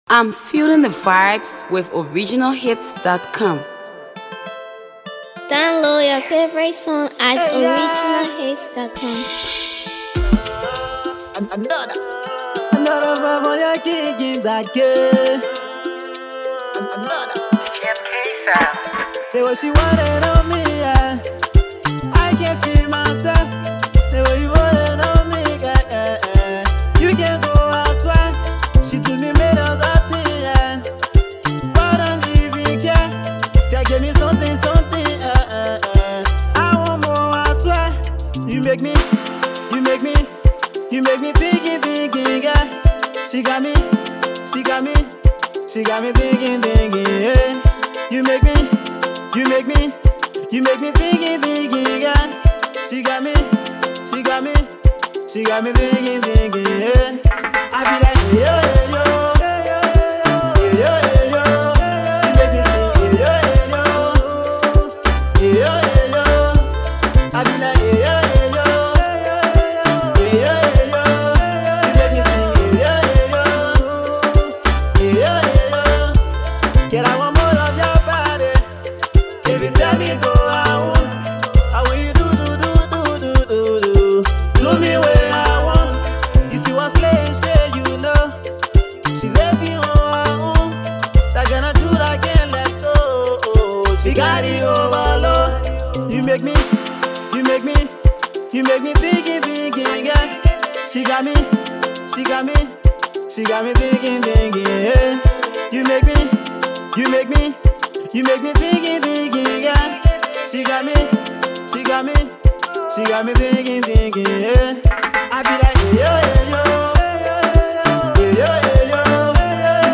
explicit lyrics banger